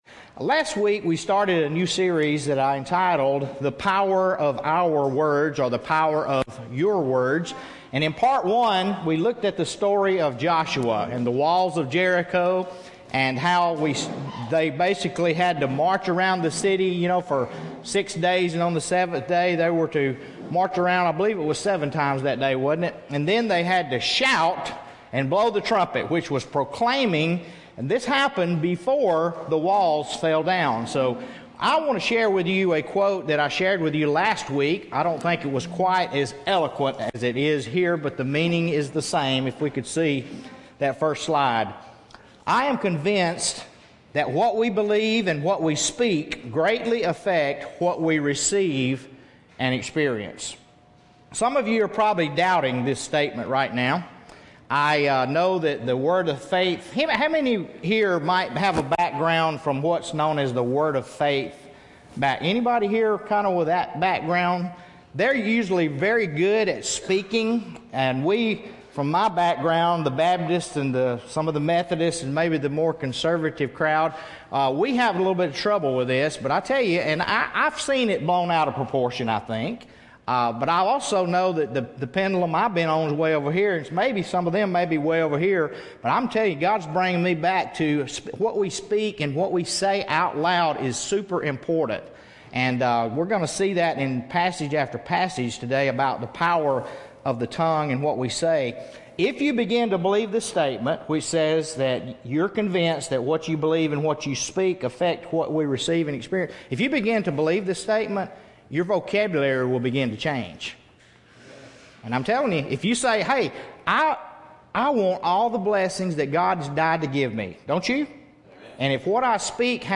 What does the mountain have to do with the fig tree? This inspiring message will answer these questions and more.